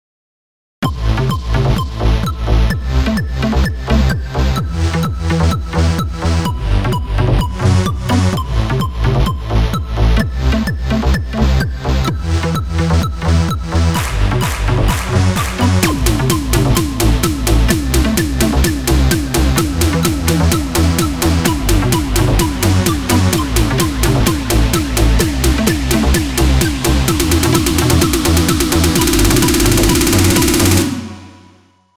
מקצבים שלי